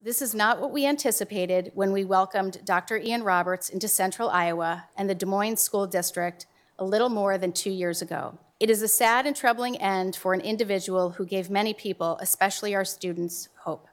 School Board President Jackie Norris says it’s the right decision given the circumstances.